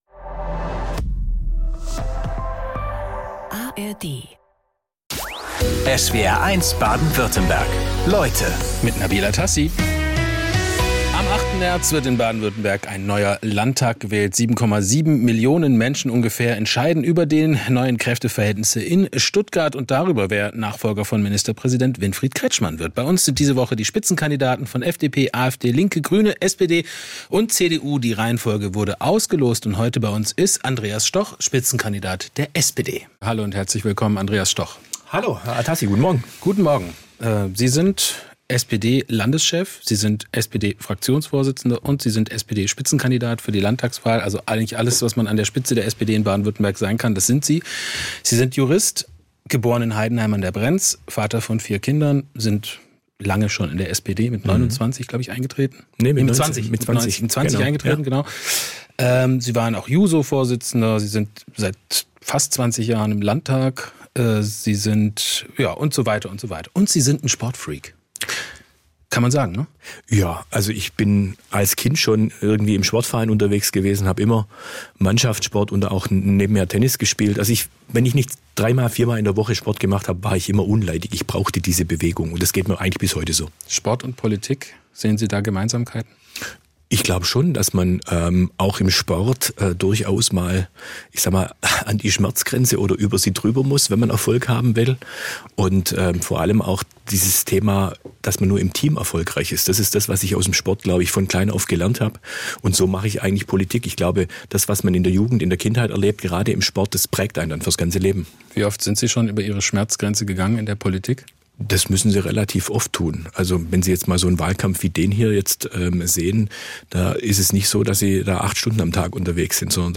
Beschreibung vor 1 Monat Vor der Landtagswahl 2026 sind Spitzenpolitiker:innen aus Baden-Württemberg zu Gast in SWR1 Leute, unter anderem Andreas Stoch von der SPD. Wir haben mit ihm über die Themen Wirtschaft, Migration, Bildung, Mobilität & Verkehr, Umwelt- und Klimaschutz gesprochen. Den Inhalt der Sendung haben wir einer Faktenüberprüfung unterzogen und die Ergebnisse in die Podcast-Folge eingebaut.